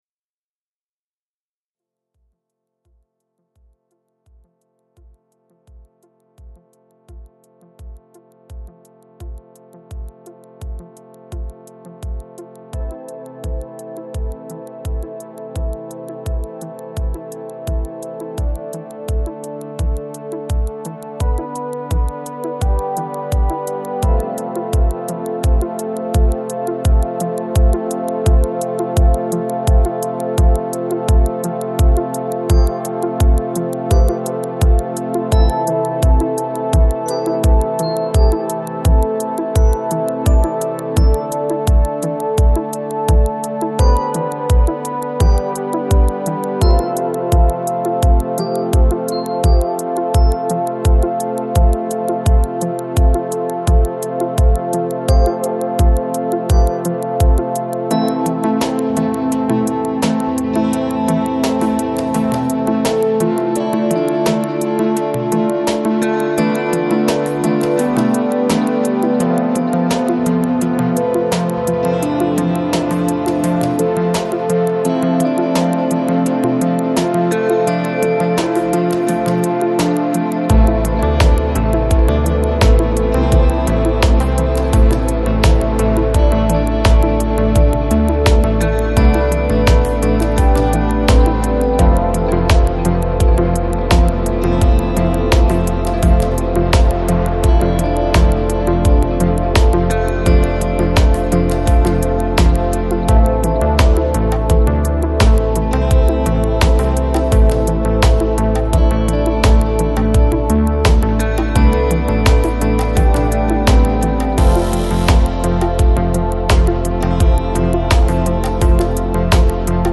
Жанр: Chillout, Chillwave, Lounge